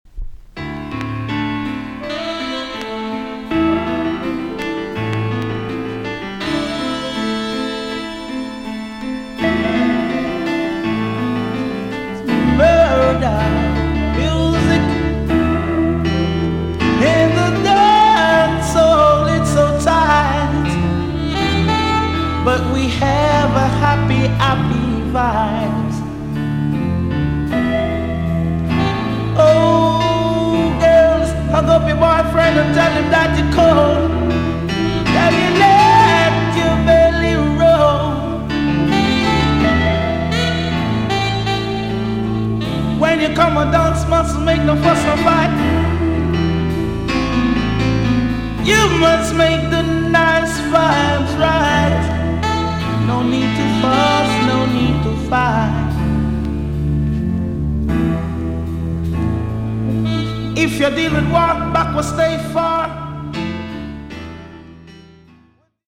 TOP >LP >80'S 90'S DANCEHALL
B.SIDE EX 音はキレイです。